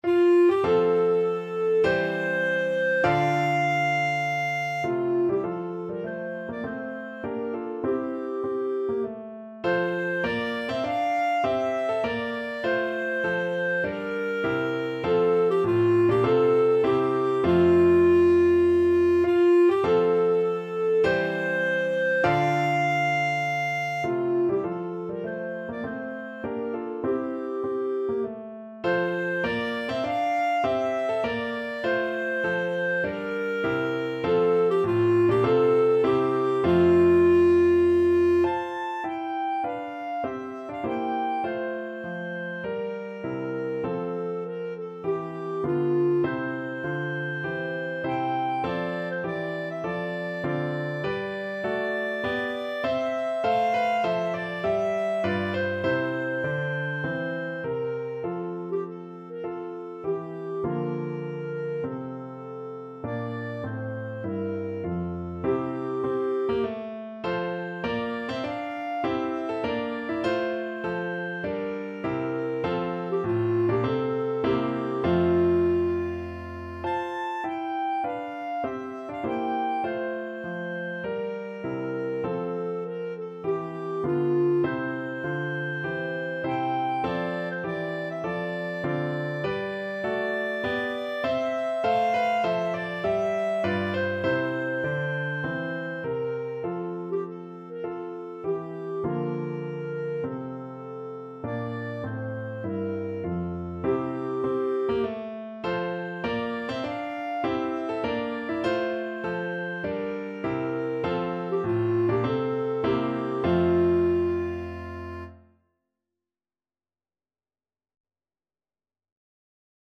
Clarinet
F major (Sounding Pitch) G major (Clarinet in Bb) (View more F major Music for Clarinet )
4/4 (View more 4/4 Music)
Classical (View more Classical Clarinet Music)